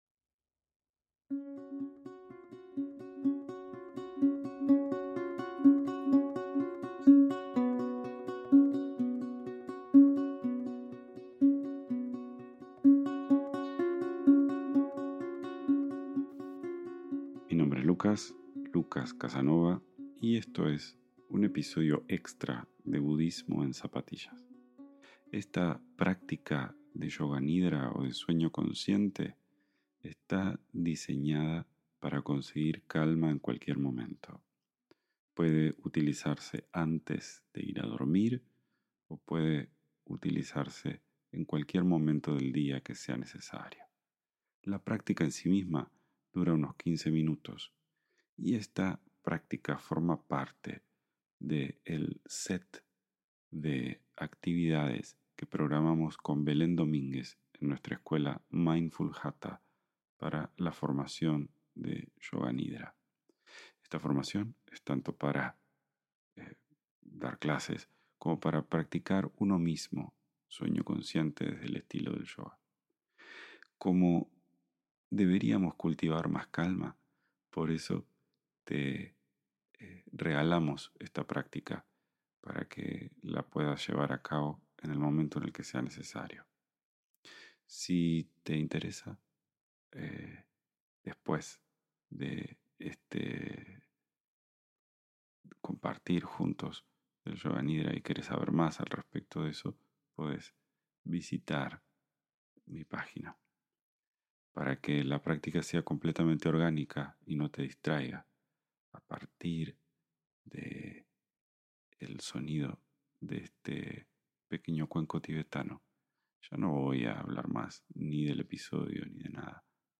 ATENCIÓN: Este audio no tiene "cierre" sino que hablo en la apertura durante 2 minutos para contarte qué es, y luego te adentras en esta meditación ideal para hacer antes de irte a dormir (así no te distraigo al final).